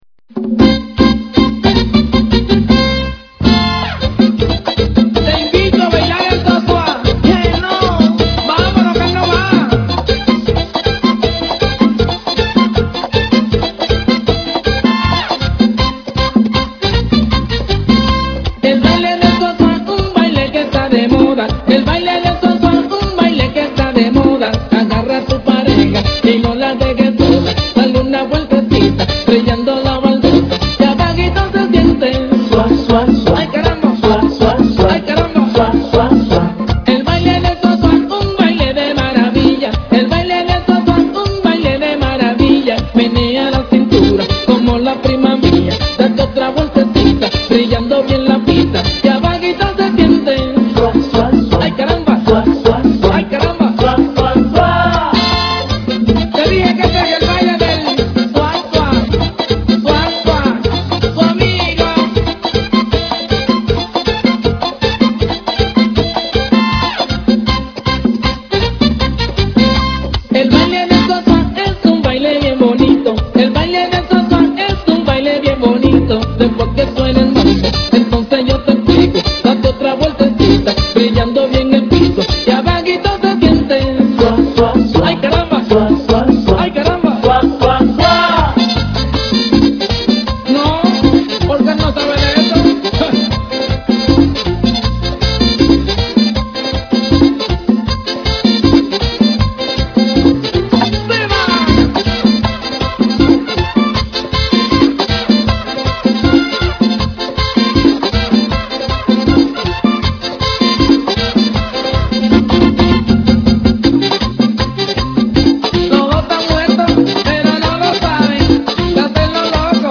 Merengue Contemporáneo